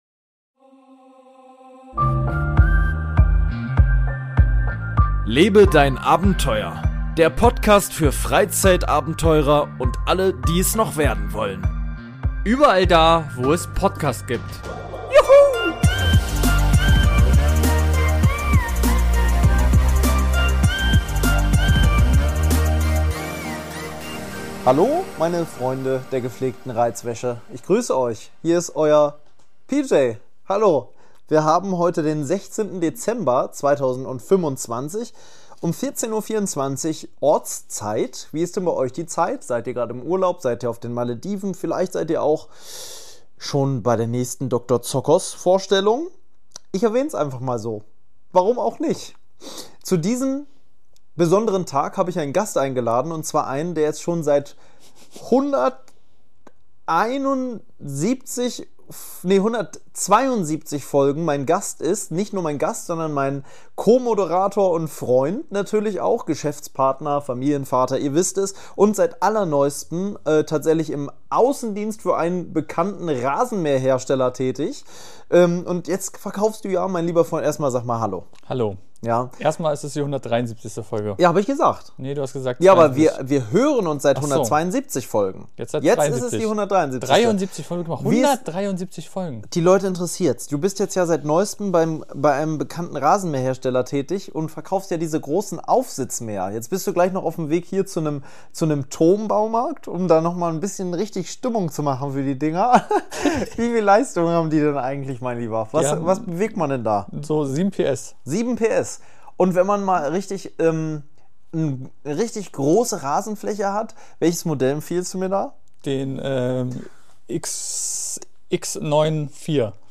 Zumindest versuchen Sie es denn die Technik macht mal wieder Probleme und so wird diese Folge zum ungewollten Chaos.